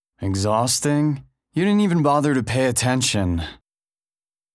Cartoon_Male_Jax.wav